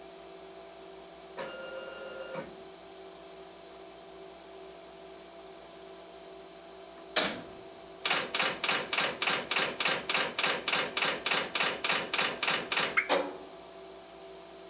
連続印字
録音機：SONY 録再Hi-MDウォークマン MZ-RH1　リニアPCMステレオ録音
電子制御された手動写植機の音です。電動のため動作音は力強く、パルスモーターやブザーによる電気的な音色が特徴です。
連続印字　行頭復帰→（連続印字スイッチ入）→枠固定→連続印字→終了ブザー→解除　という流れです。高速シャッター音が聴きどころです。